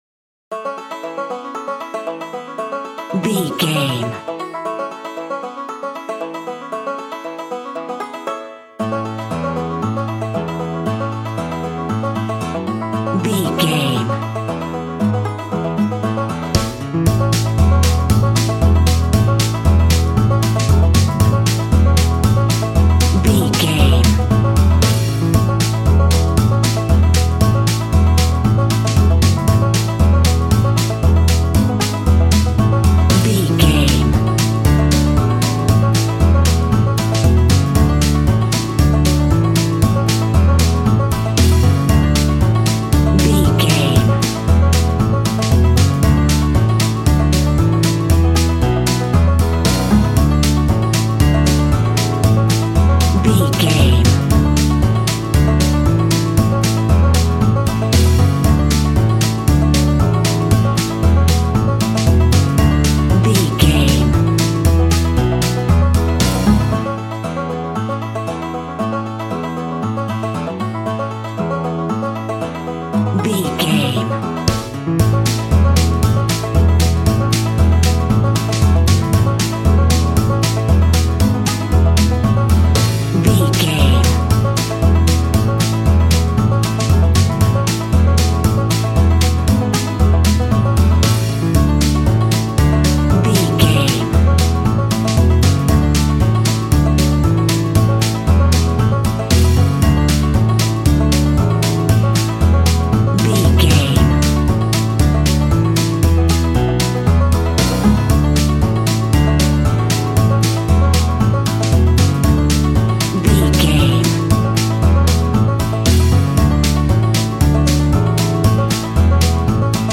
Ionian/Major
Fast
fun
bouncy
positive
double bass
drums
acoustic guitar